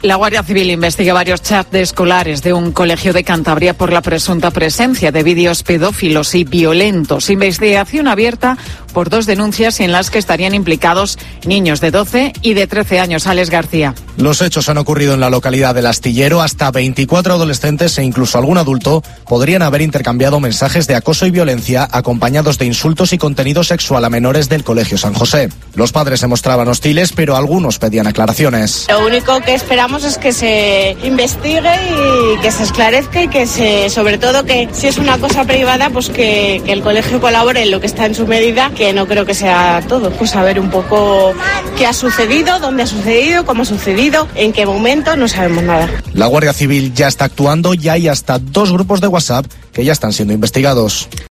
Los padres hablan ante el escándalo de El Astillero: "Queremos que todo se aclare lo antes posible"